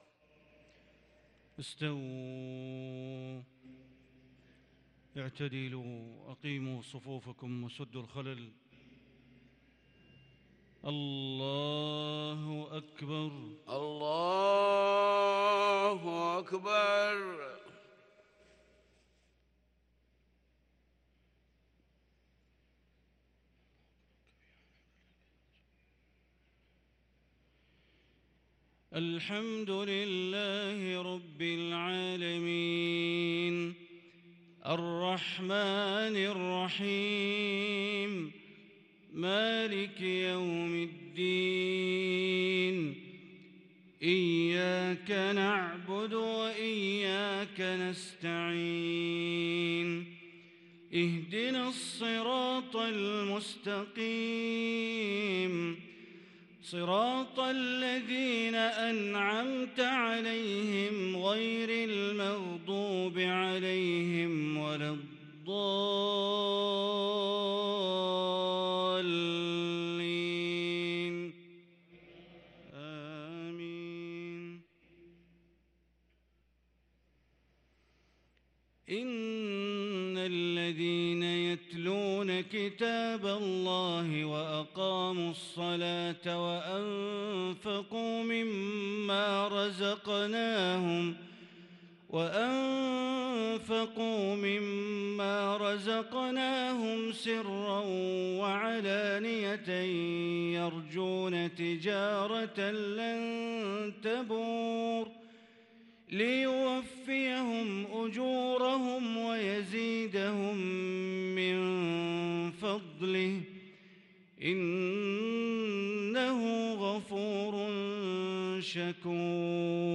صلاة العشاء للقارئ بندر بليلة 9 جمادي الأول 1444 هـ
تِلَاوَات الْحَرَمَيْن .